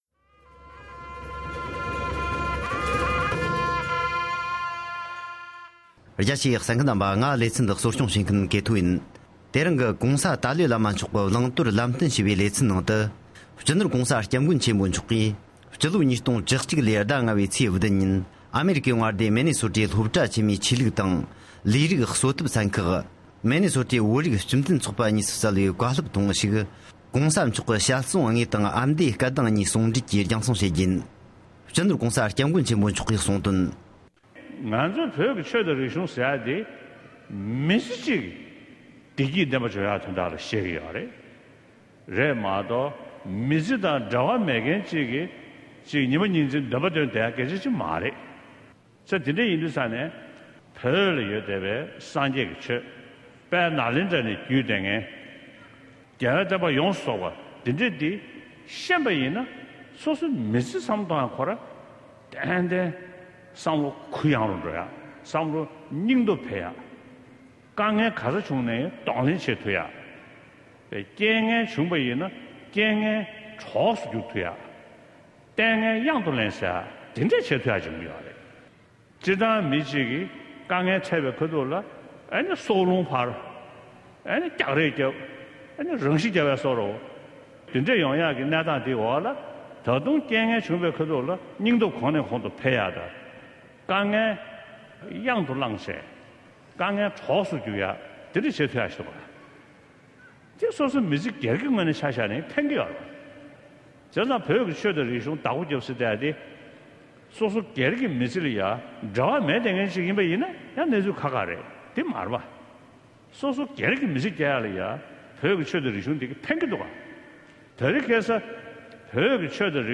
༸གོང་ས་མཆོག་ནས་མི་ནི་སོ་ཌ་བོད་མིར་བསྩལ་བའི་བཀའ་སློབ།
༸གོང་ས་མཆོག་གིས་ཨ་རིའི་མངའ་སྡེ་མི་ནི་སོ་ཌར་ནང་ཆོས་ཀྱིས་སོ་སོའི་མི་ཚེའི་ནང་གི་དཀའ་ངལ་ལ་གདོང་ལེན་བྱེད་པའི་སྐོར་བཀའ་སློབ་བསྩལ།